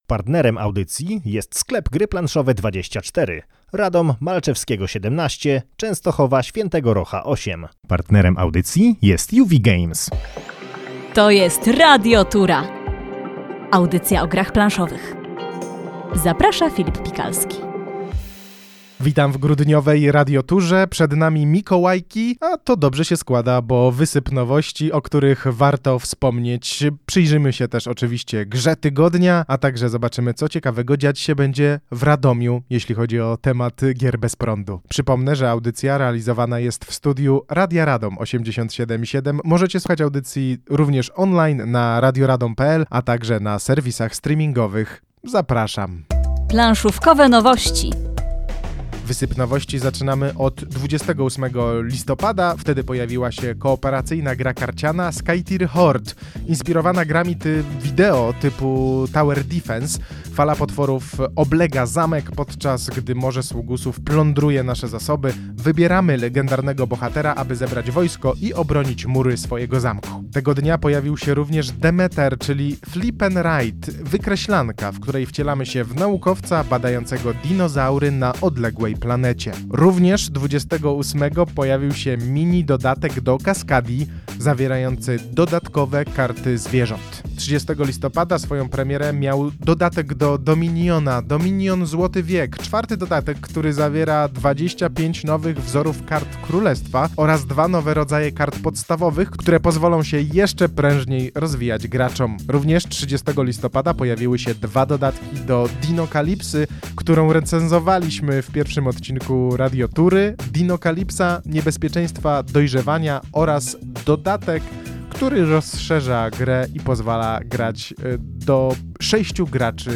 Audycja o grach planszowych w Radiu Radom na 87,7 FM.